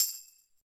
soft-slidertick.ogg